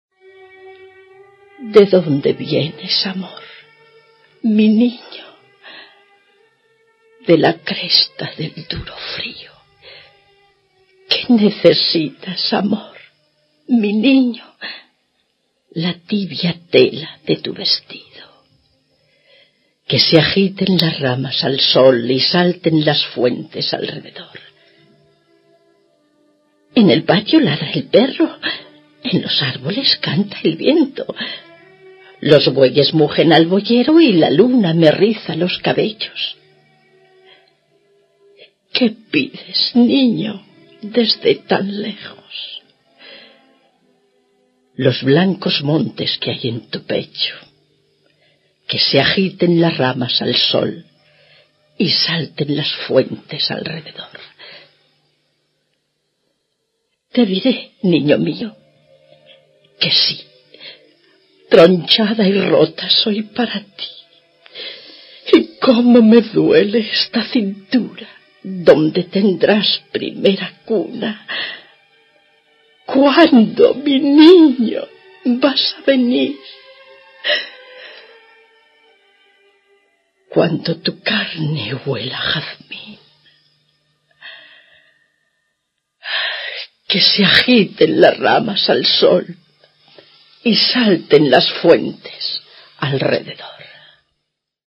Radioteatre: Yerma - Ràdio Terrassa, 1990